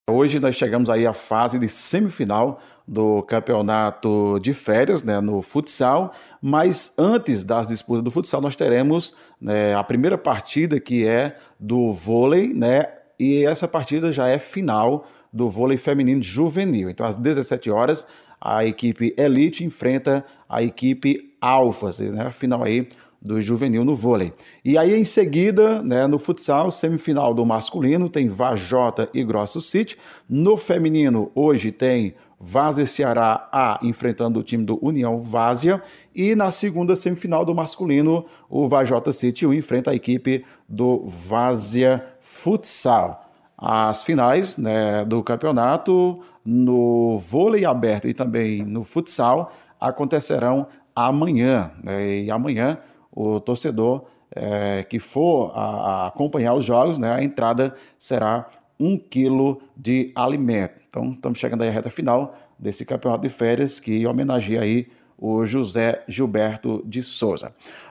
Em áudio enviado para a Rádio Cultura, o secretário de esportes Cícero Sousa disse que medidas foram tomadas.